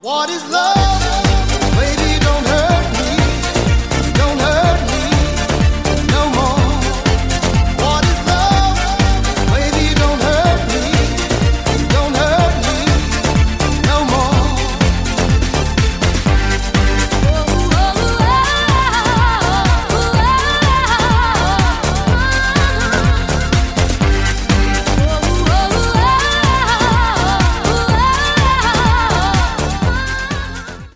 Рингтоны техно
Euro house